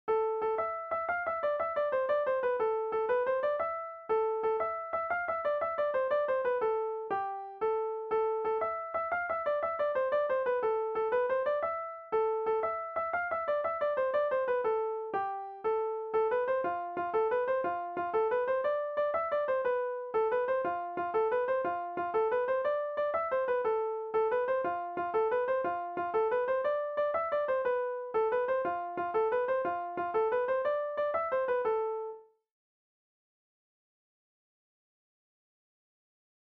danse : cercle circassien